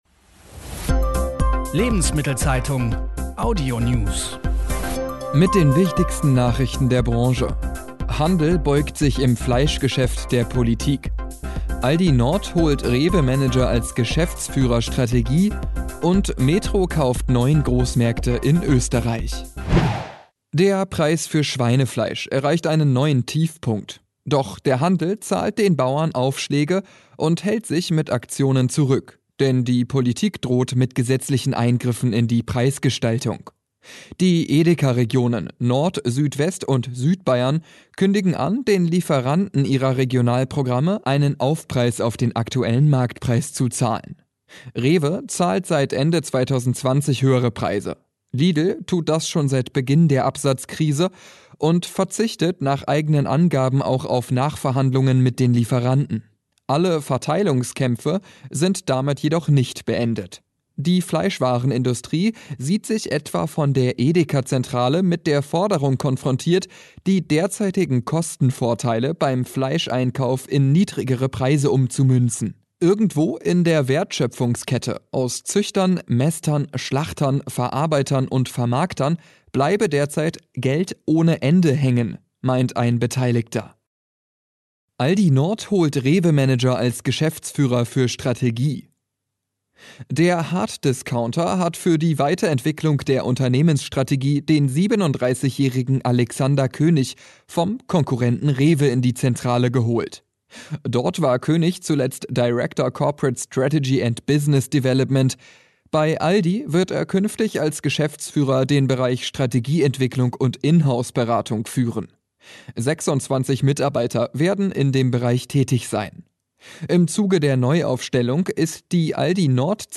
Wirtschaft , Nachrichten